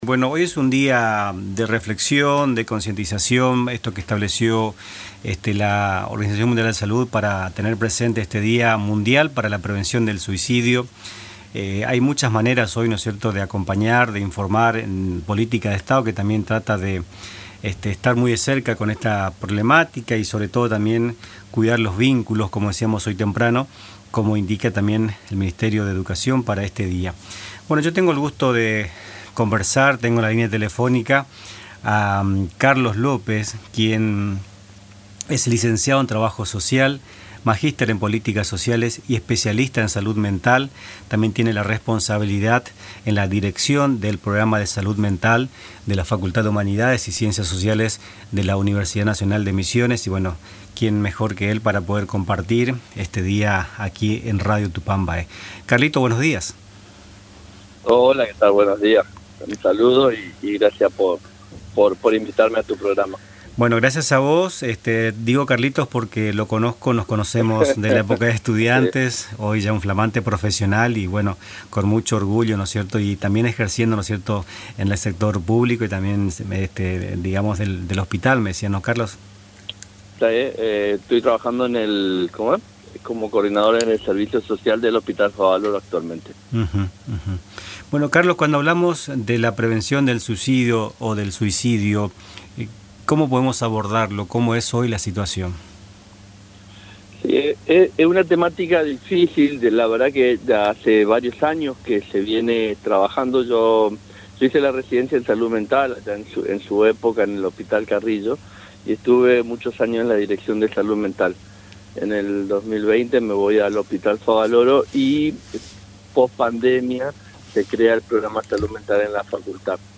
En diálogo con Nuestras Mañanas